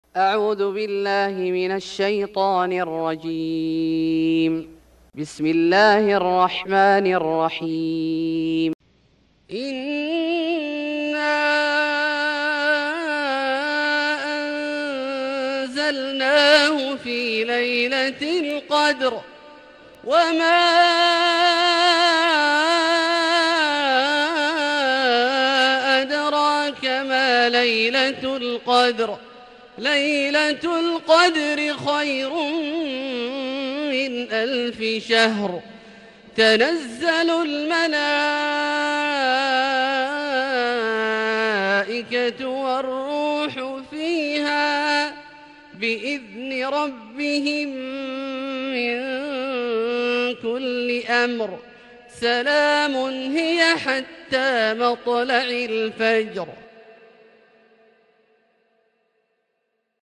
سورة القدر Surat Al-Qadr > مصحف الشيخ عبدالله الجهني من الحرم المكي > المصحف - تلاوات الحرمين